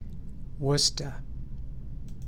I recorded my voice on a Lenovo lapop
Summary Description Worcester Pronunciation.ogg English: The pronunciation of the Worcester city name.
Worcester_Pronunciation.ogg